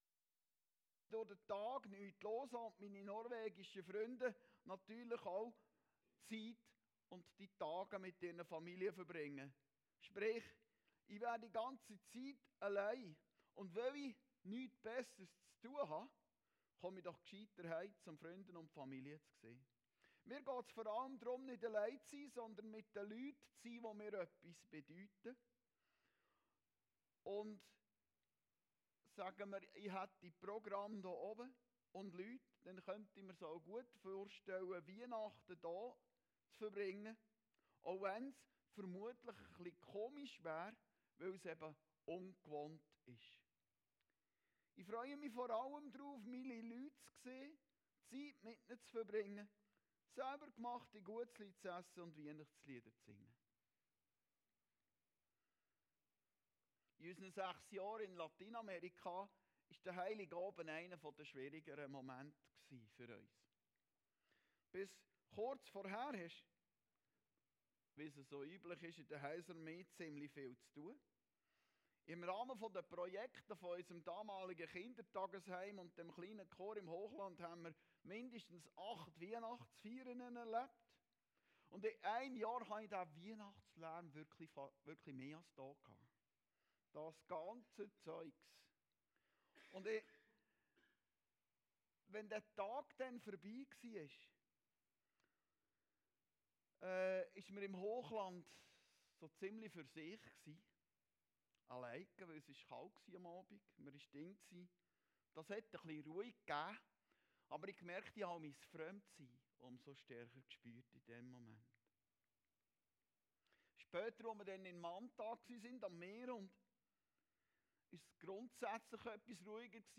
Predigten Heilsarmee Aargau Süd – Home for Christmas